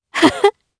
Chrisha-Vox_Happy2_jp.wav